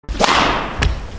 알림음 8_Smashing1.mp3